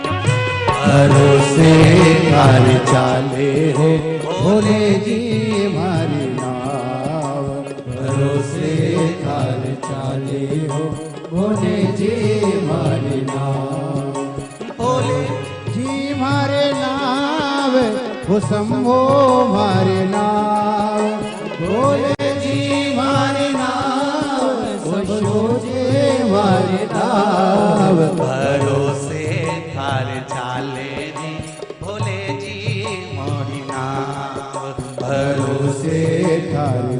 शिव भजन रिंगटोन